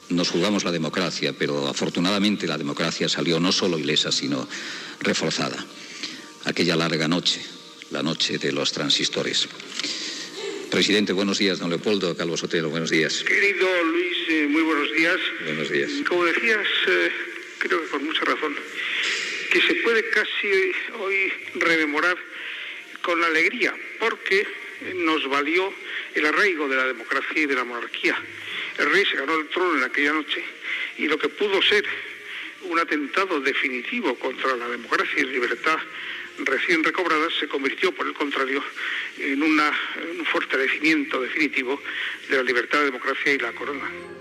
Fragment d'una entrevista al president del Govern espanyol Leopoldo Calvo Sotelo recordant l'intent del cop d'Estat del 23 de febrer del 1981
Info-entreteniment